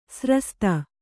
♪ srasta